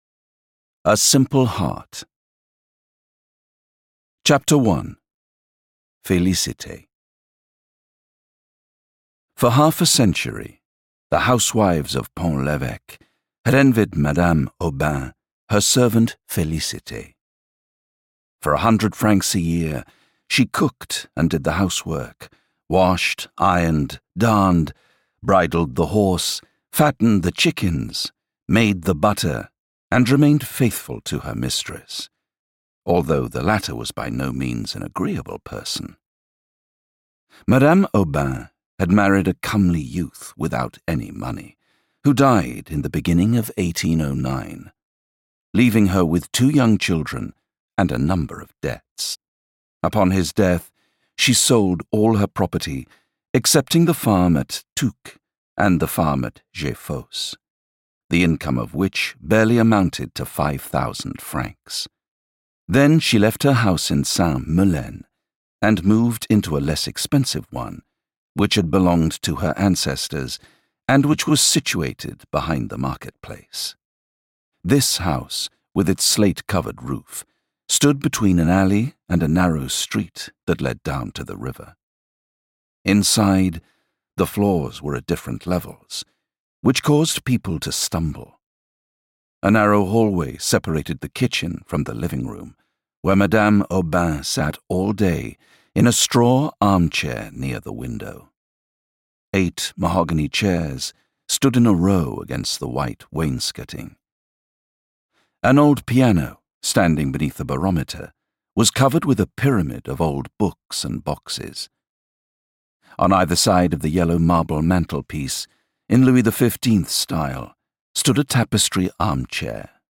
Three Tales (EN) audiokniha
Ukázka z knihy